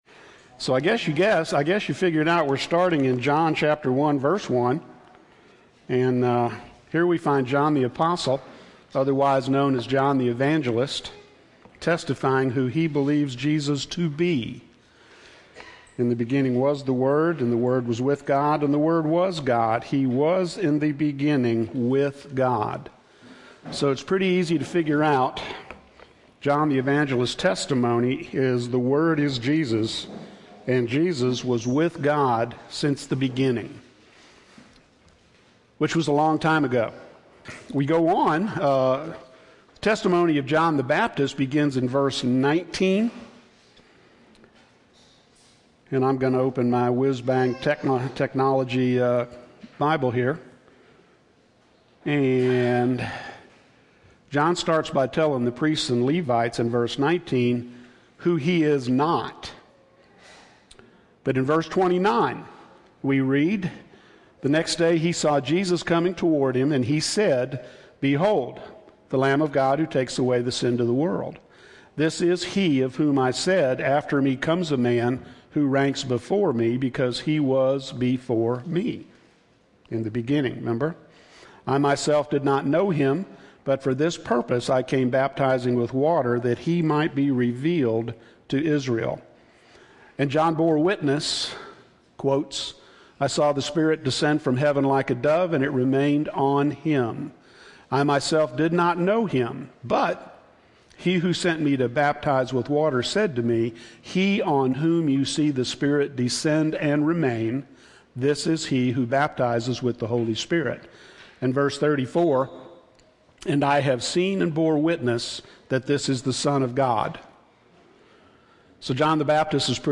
During the teaching today we watched a portion of this movie our particular section was 25:17 through 31:07 on the "original" 3 hour version